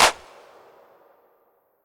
Metro Claps [Hard Clap].wav